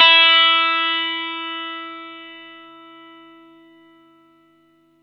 R12NOTE E +2.wav